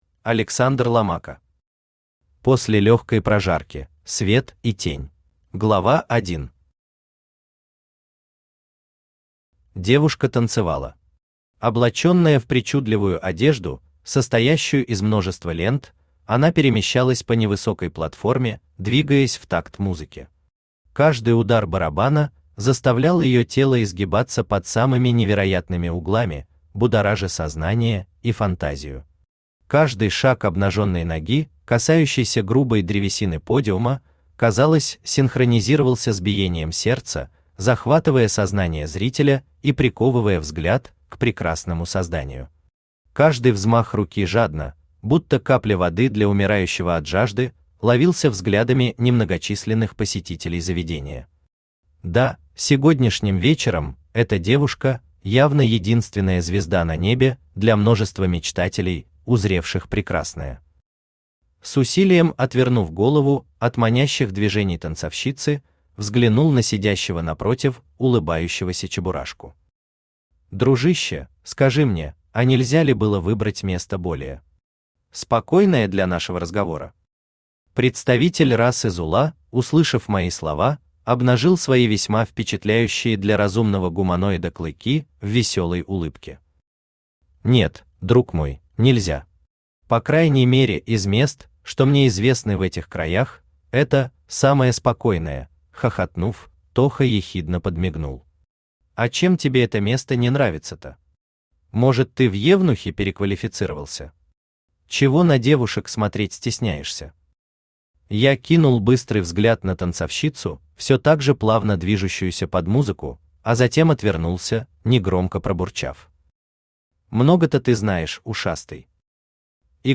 Aудиокнига После Лёгкой Прожарки: свет и тень Автор Александр Ломако Читает аудиокнигу Авточтец ЛитРес.